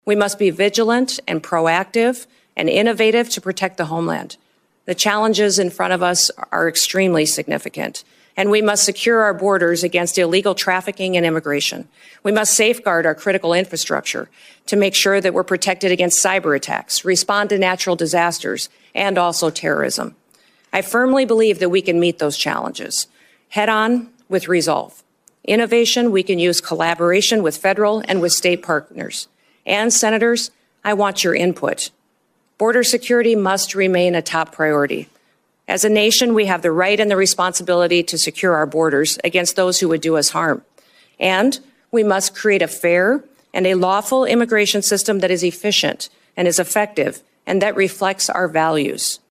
WASHINGTON, D.C.(DRGNews)- South Dakota Governor Kristi Noem answered questions from members of the United States Senate Homeland Security and Governmental Affairs Committee Friday during her confirmation hearing to become President-elect Donald Trump’s Secretary of Homeland Security.